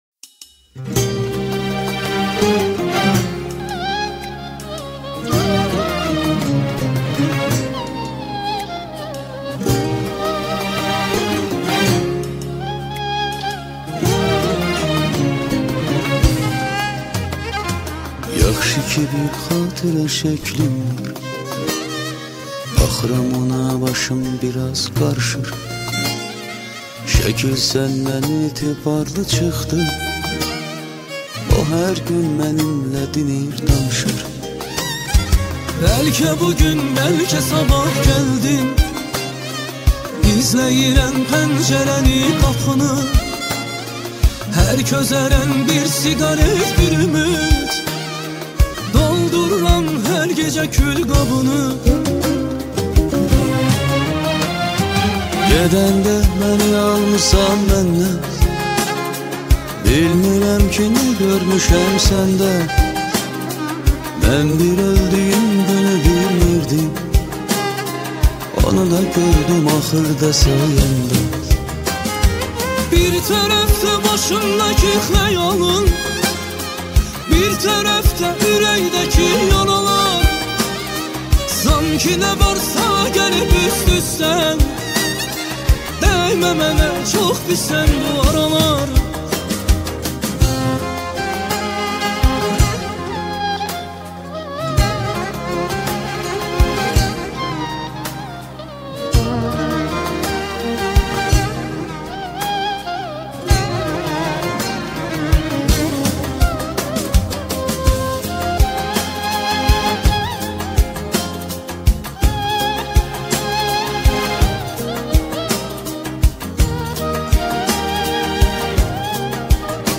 آهنگ ترکی